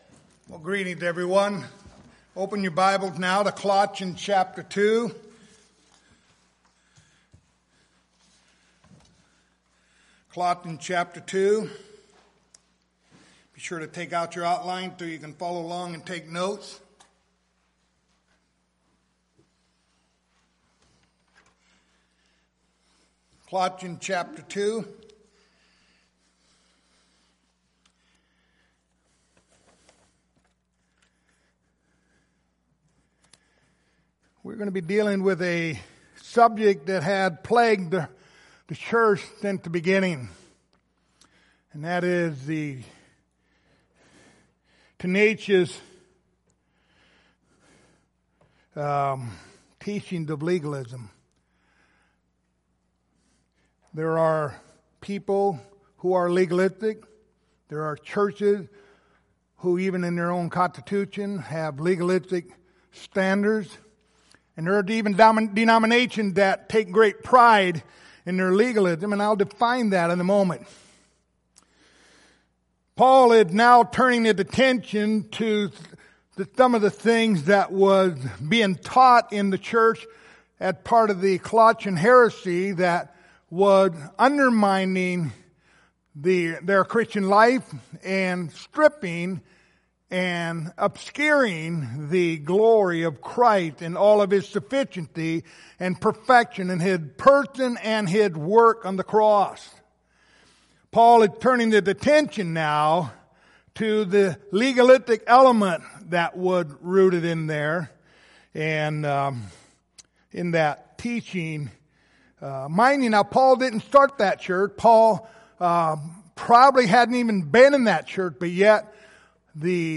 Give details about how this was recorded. Passage: Colossians 2:16-17 Service Type: Sunday Morning